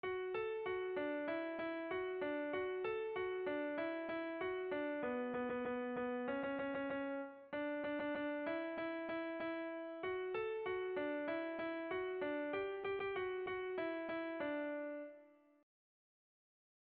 Bertso melodies - View details   To know more about this section
Haurrentzakoa
AB